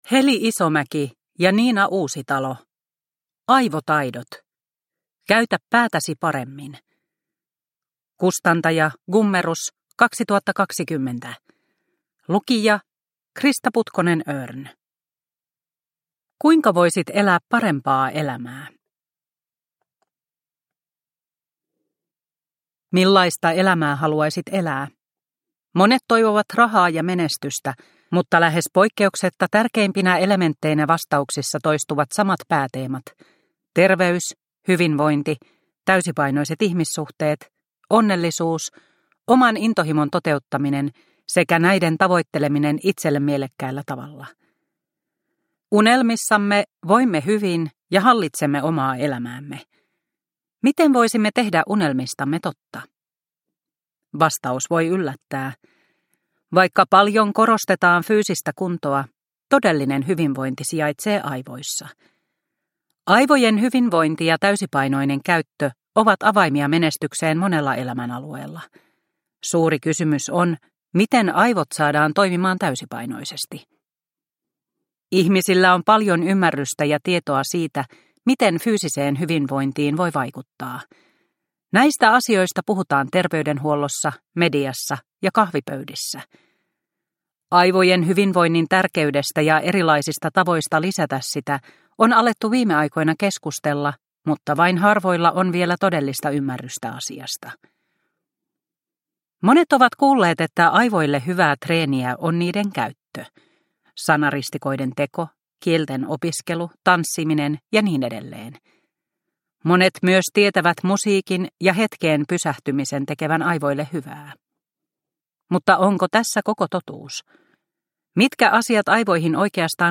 Aivotaidot – Ljudbok – Laddas ner